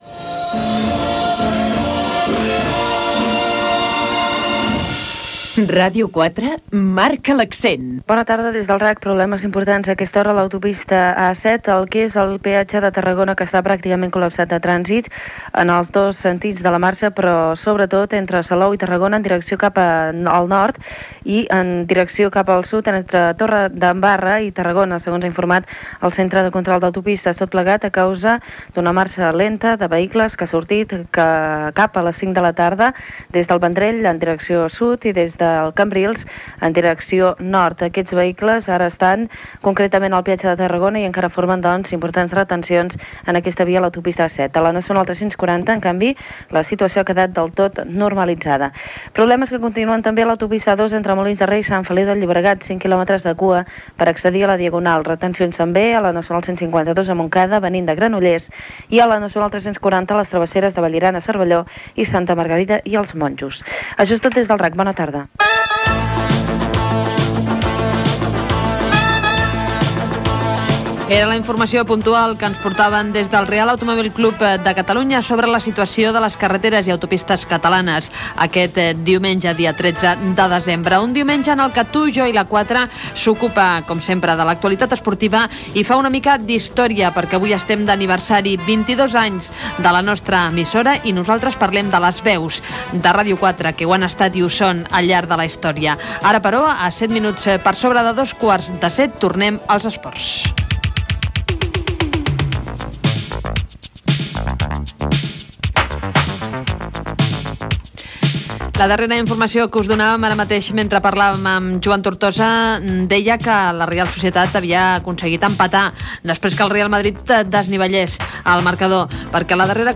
Indicatiu de l'emissora, informació del trànsit des del RAC, hora, informació esportiva, indicatiu
Info-entreteniment